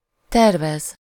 Ääntäminen
IPA: /pʁɔʒ.te/